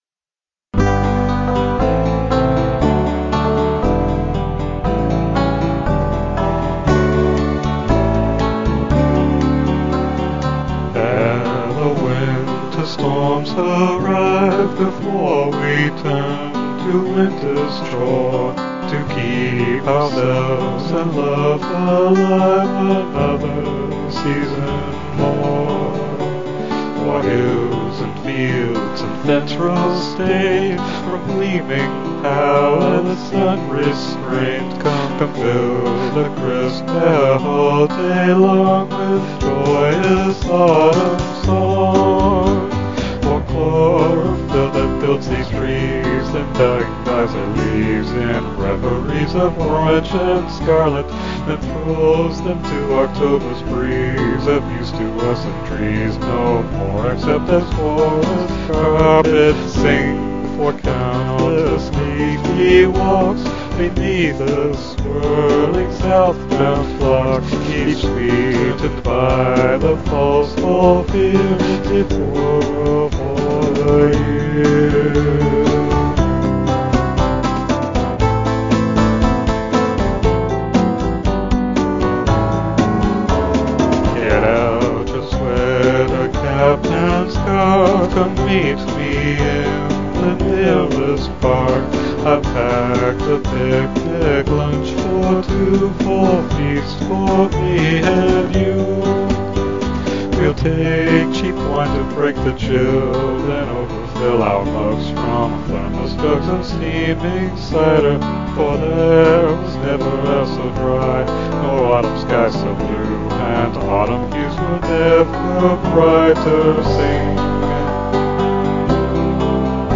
slow 4/4 choral or folk voices